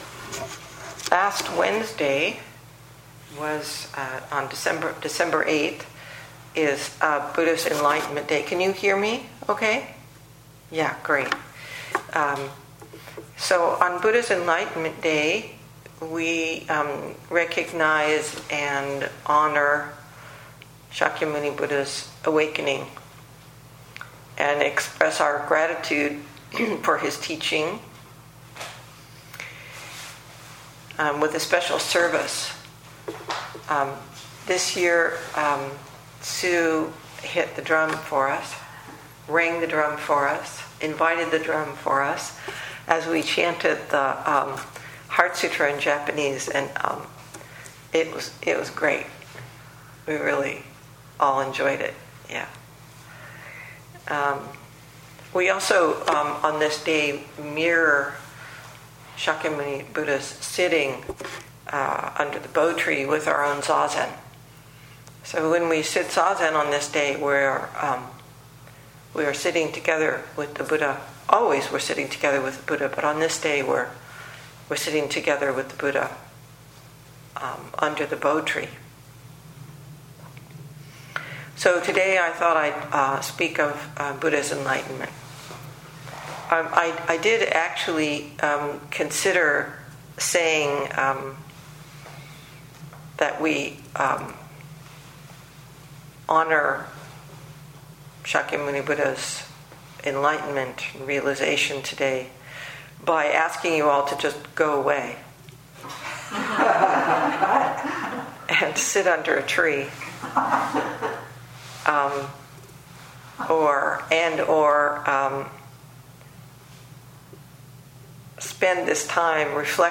2021 in Dharma Talks